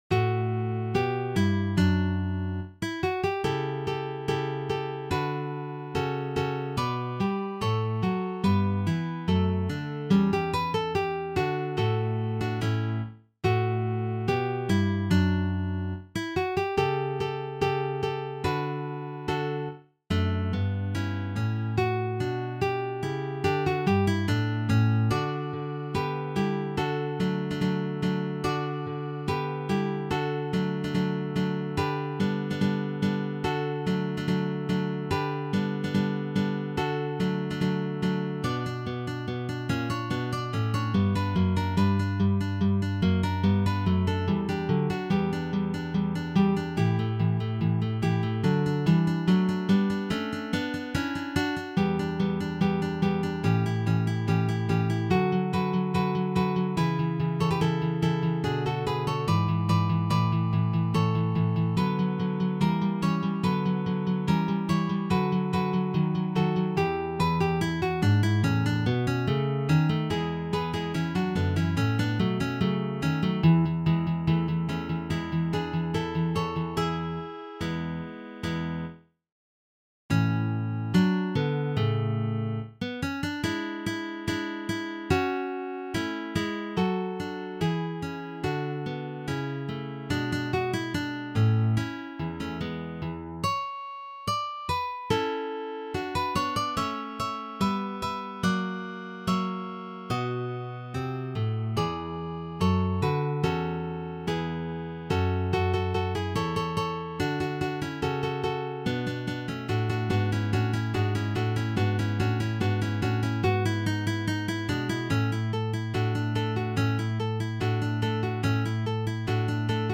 a duet written for two guitars